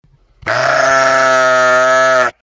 Звуки, которые издают овцы и бараны в нескольких вариантах.
13. Звук злого барана